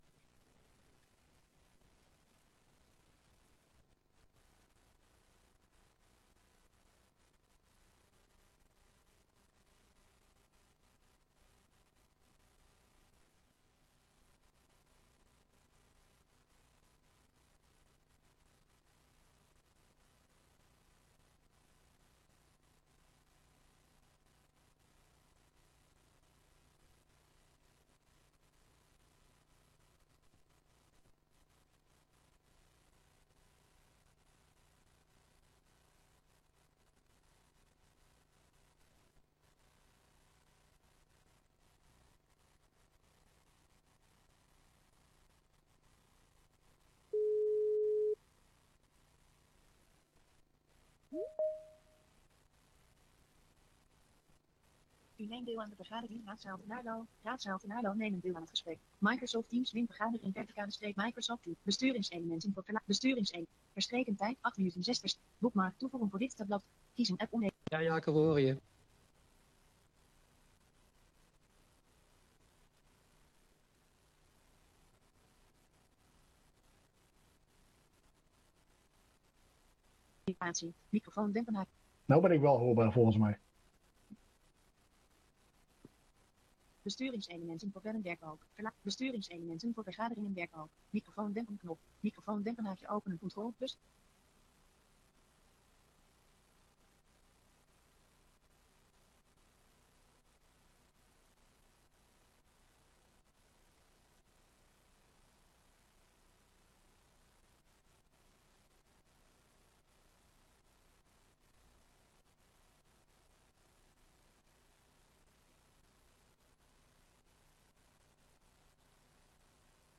Raadsbijeenkomst 25 februari 2025 19:30:00, Gemeente Tynaarlo
Locatie: Raadszaal